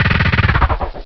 DRILL3.WAV